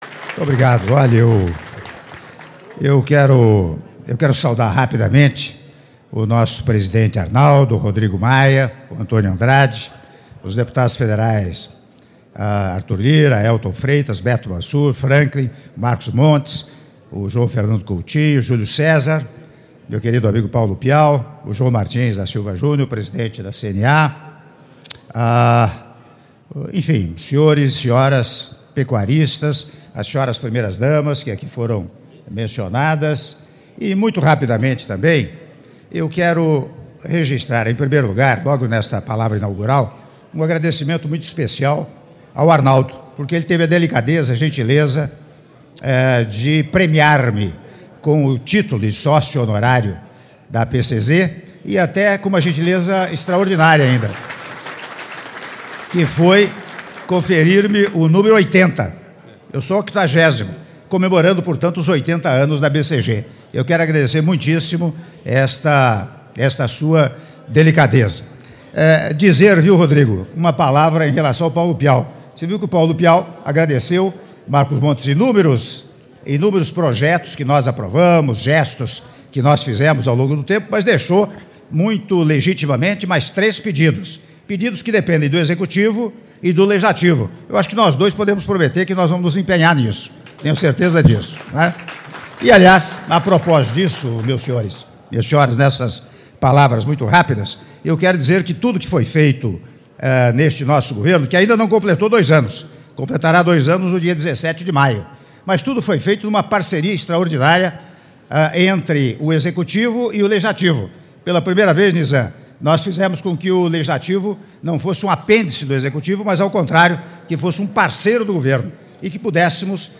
Áudio do discurso do Presidente da República, Michel Temer, durante a solenidade de Abertura Oficial da 84ª Exposição Internacional de Gado Zebu - ExpoZebu 2018- Uberaba/MG- (08min19s)